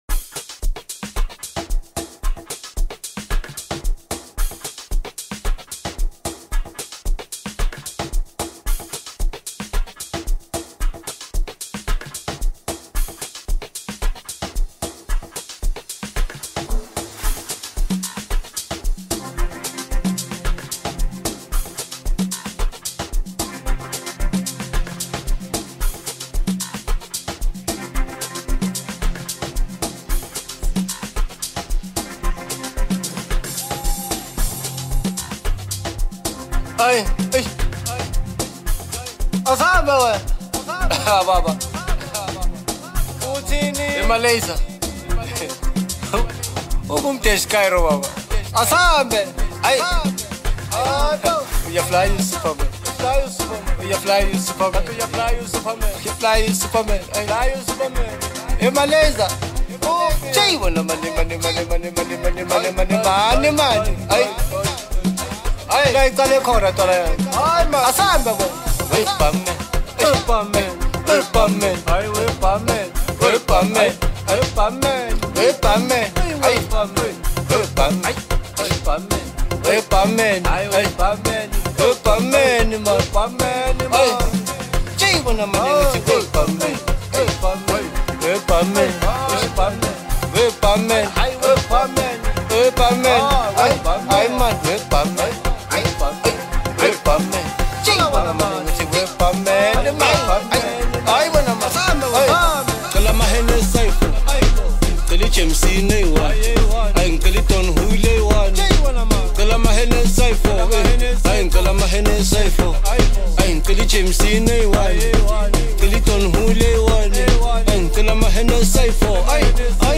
Home » Amapiano
South African singer